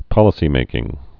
(pŏlĭ-sē-mākĭng)